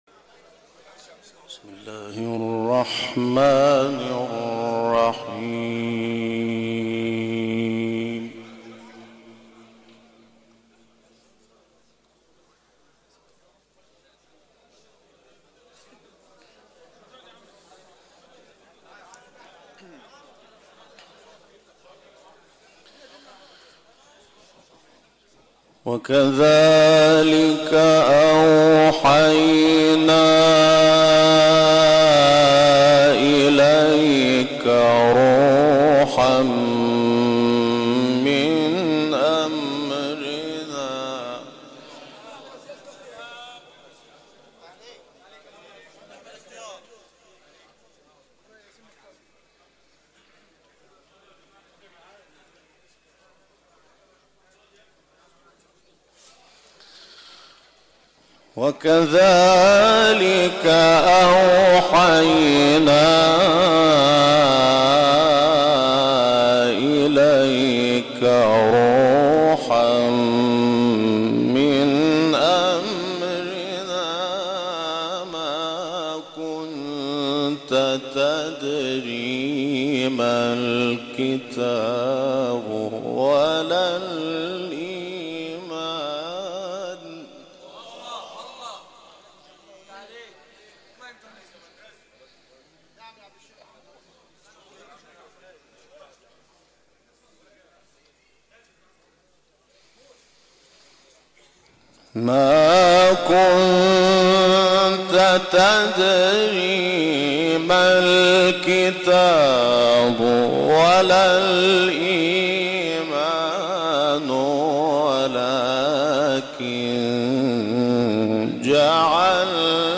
گروه شبکه اجتماعی ــ تلاوت جدید محمود شحات انور از سوره‌های مبارکه شوری، شمس و قریش ارائه می‌شود.